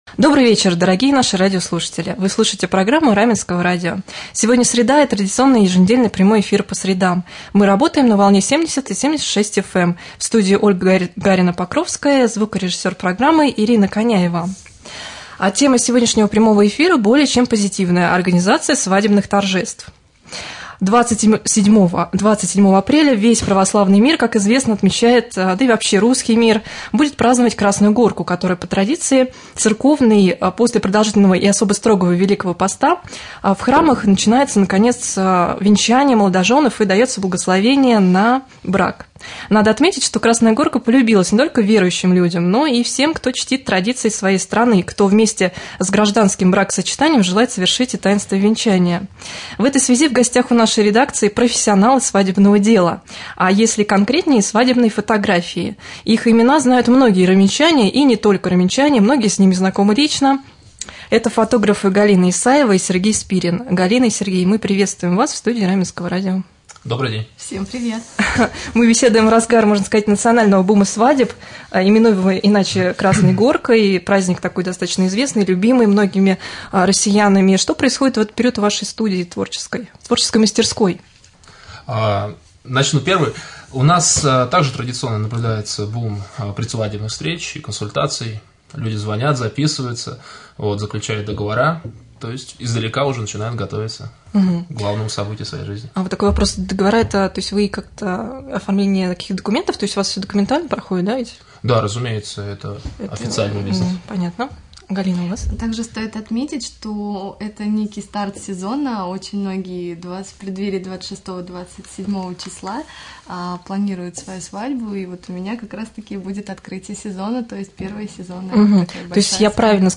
2.Прямой-эфир.mp3